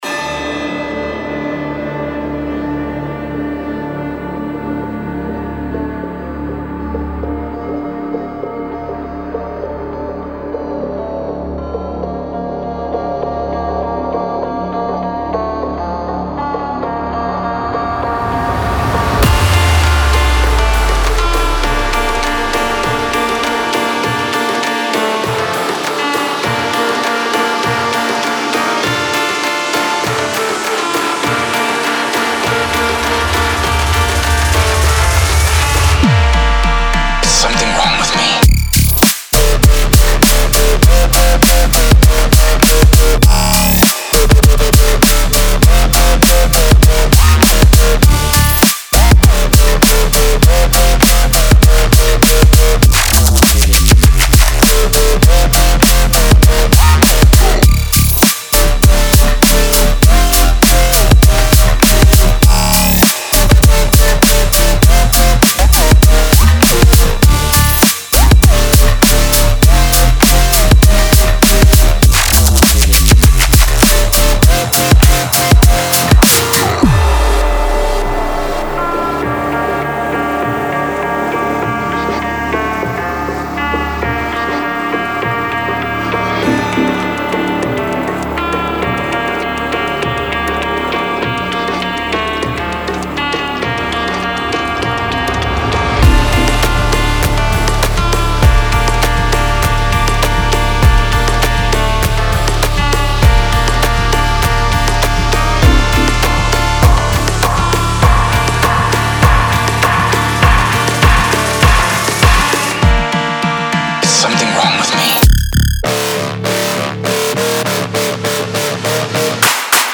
BPM100-100
Audio QualityPerfect (High Quality)
Full Length Song (not arcade length cut)